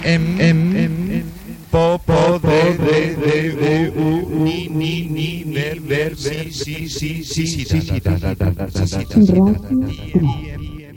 Indicatiu de la ràdio